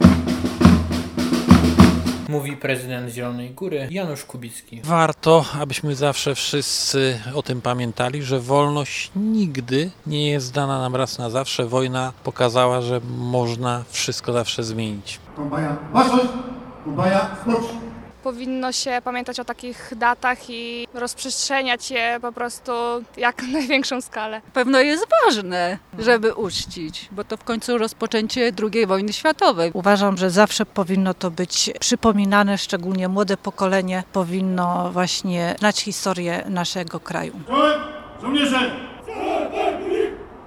O 11:00 uroczystą mszą św. w kościele pw. Najświętszego Zbawiciela rozpoczęły się miejskie uroczystości z okazji 81. rocznicy wybuchu II Wojny Światowej. Następnie przemarszem a później uroczystym apelem na placu Bohaterów II Wojny Światowej uczczono pamięć poległych żołnierzy.